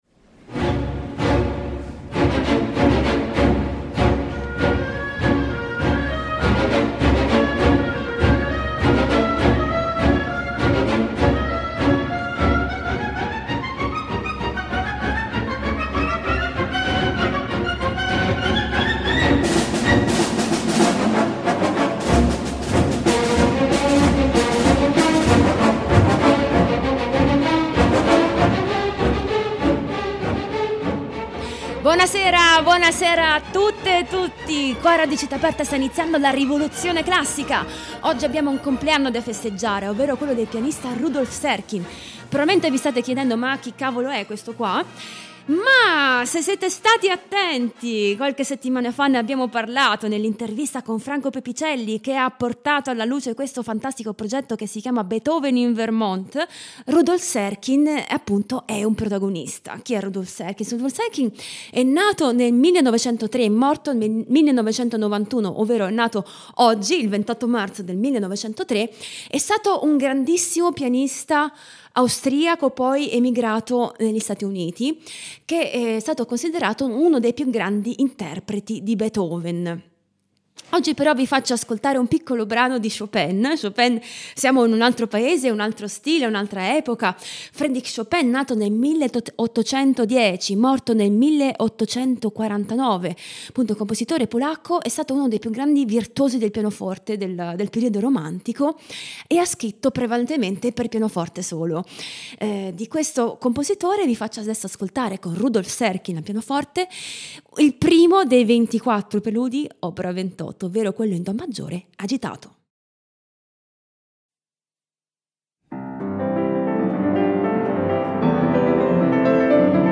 Ospite di questa puntata la pianista